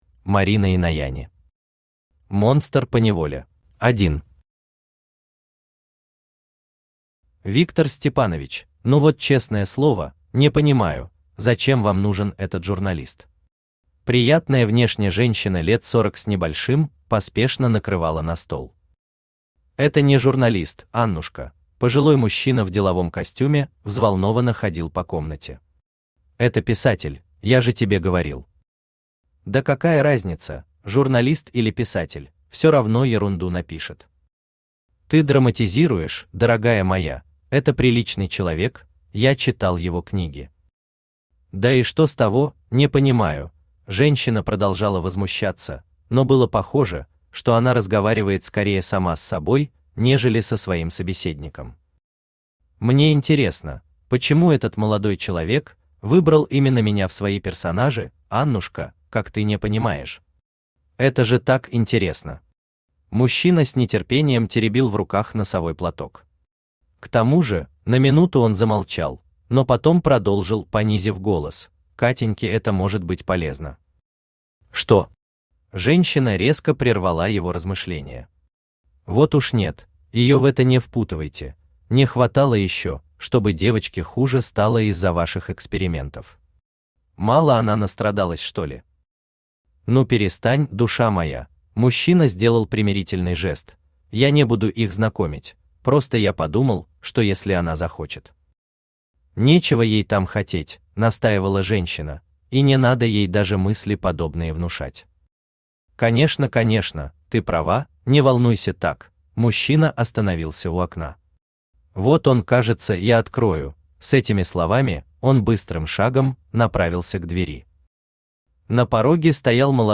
Аудиокнига Монстр поневоле | Библиотека аудиокниг
Aудиокнига Монстр поневоле Автор Марина Инаяни Читает аудиокнигу Авточтец ЛитРес.